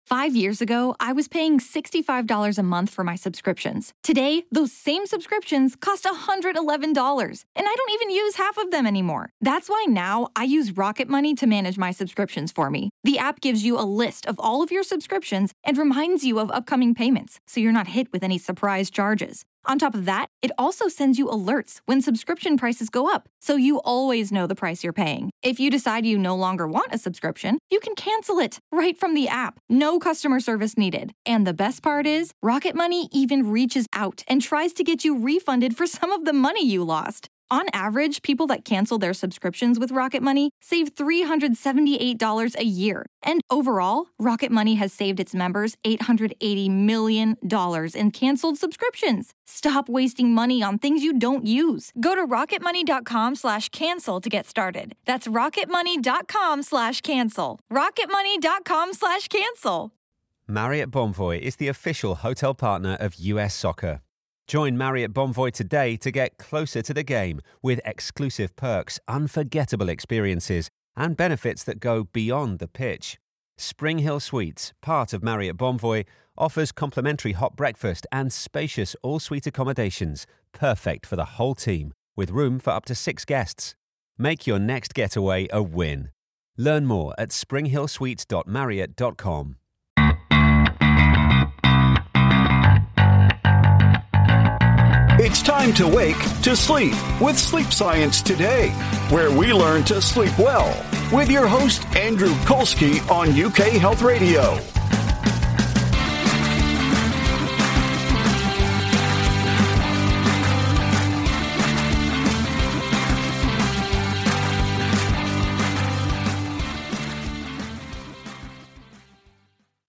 Doctors have learned that restful sleep is critical for your physical and mental health. You will hear from renowned sleep experts as they share the latest information about how to sleep better with science.